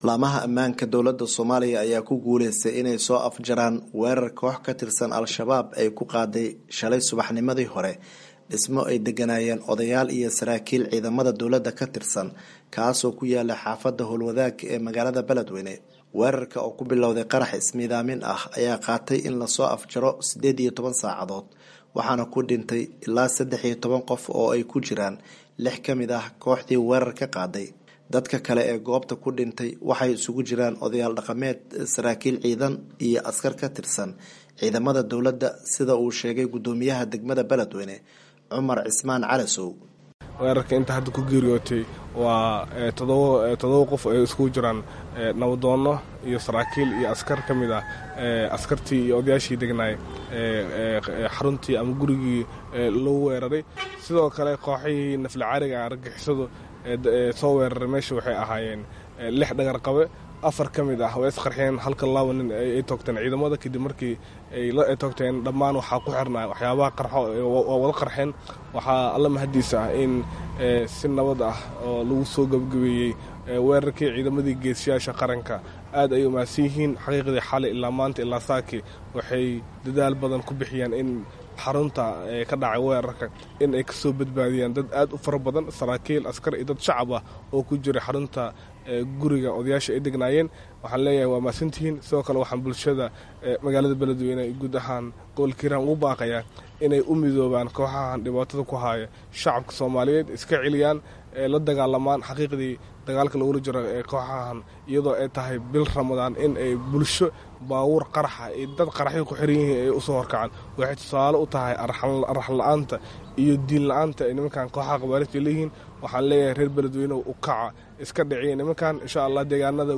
War Deg-Deg ah